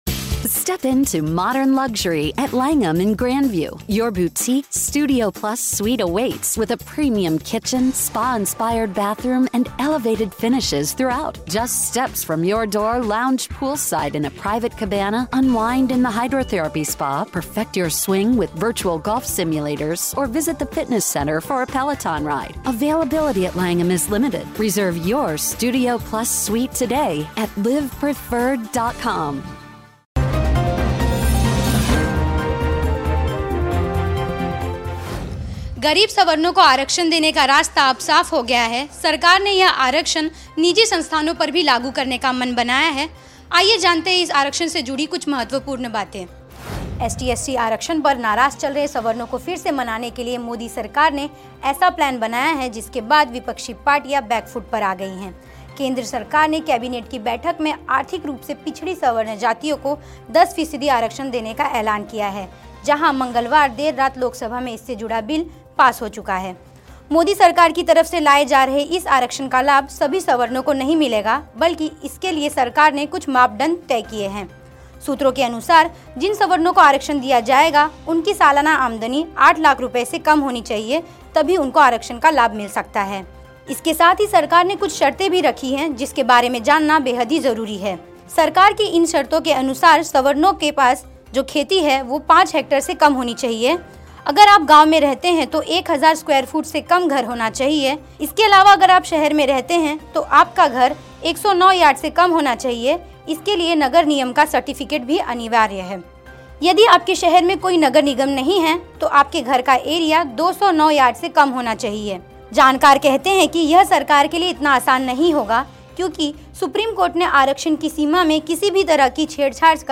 न्यूज़ रिपोर्ट - News Report Hindi / सवर्ण आरक्षण बिल में क्या खास है !